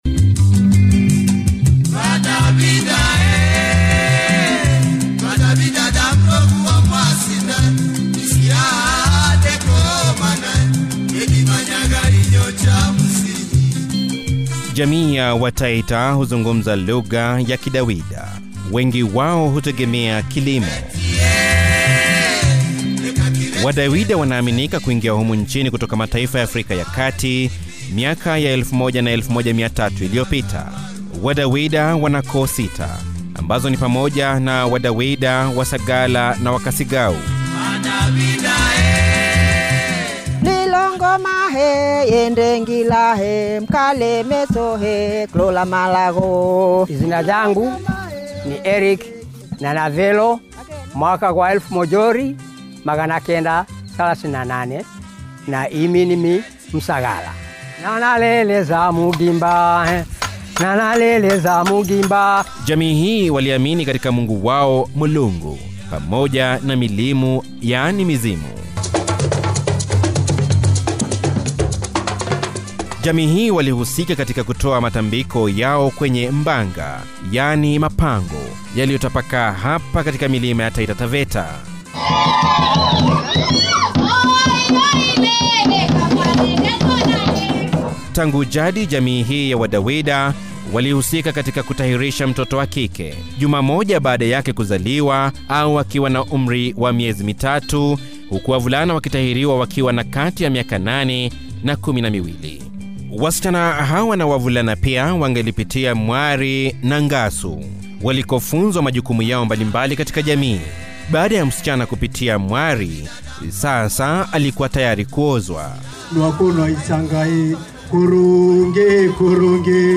MAKALA YA RADIO;
MAKALA-YA-RADIOUTAMADUNI-WA-JAMII-YA-TAITA-.mp3